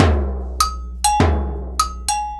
100 AGOGO 03.wav